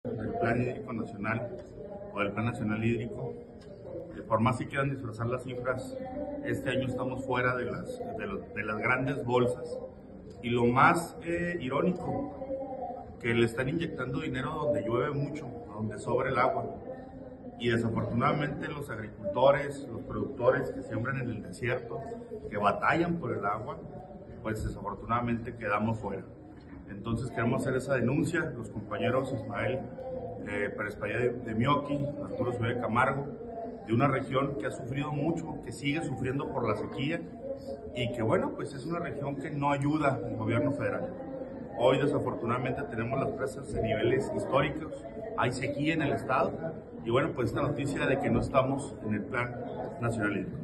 El coordinador de la bancada Alfredo Chávez, dijo que lo más irónico es que la administración federal destinó recursos donde “llueve mucho y sobra el agua”, y señaló que los agricultores en la zona desértica de la entidad quedan fuera.